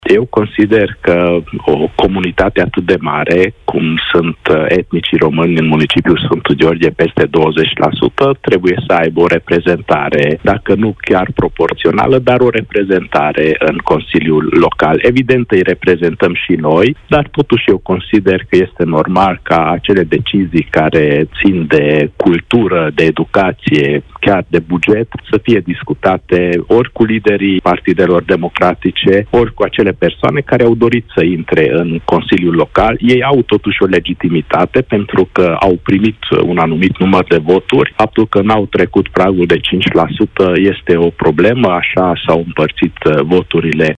Reales pentru al cincilea mandat, primarul din Sfântu Gheorghe, Antal Arpad a spus în emisiunea „Deșteptarea”, la Europa FM, că intenționează să înființeze un consiliu consultativ care să-i reprezinte pe români.
Antal Arpad, primarul orașului Sfântu Gheorghe: „O comunitate atât de mare, cum sunt etnicii români în municipiul Sfântu Gheorghe, peste 20%, trebuie să aibă o reprezentare”
12iun-12-Arpad-consiliu-consultativ.mp3